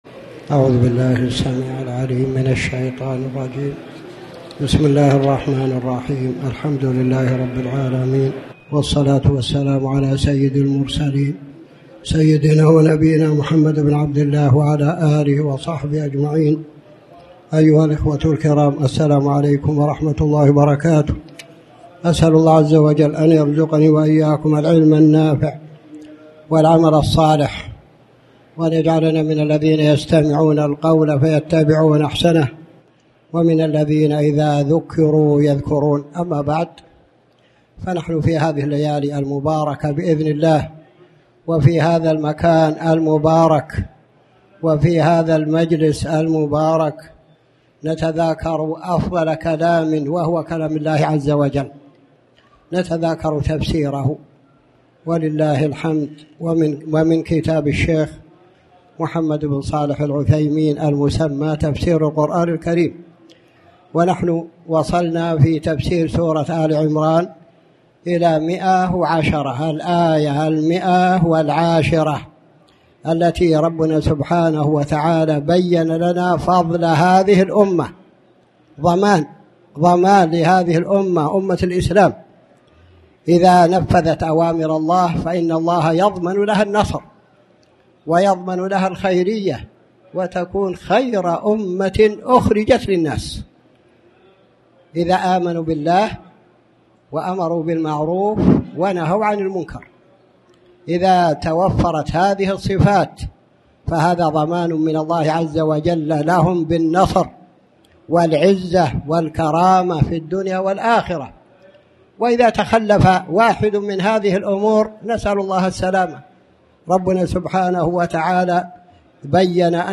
تاريخ النشر ٢٨ ربيع الأول ١٤٣٩ هـ المكان: المسجد الحرام الشيخ